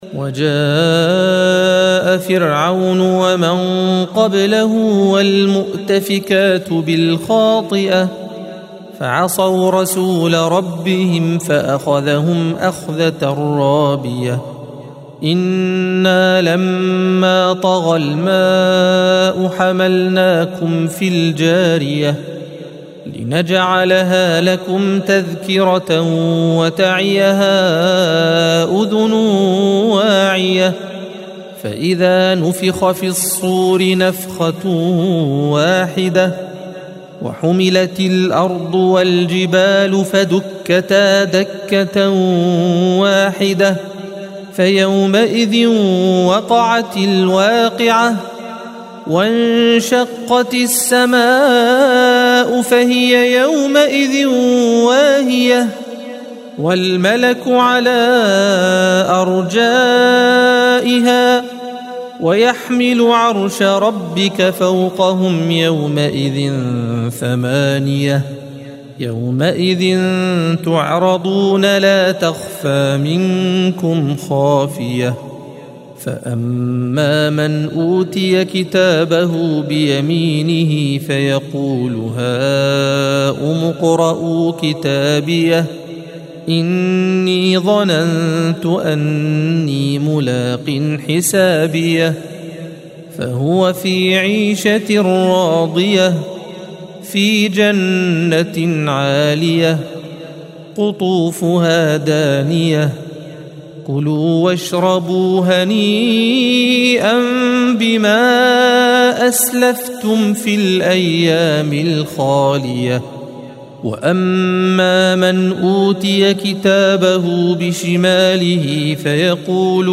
الصفحة 567 - القارئ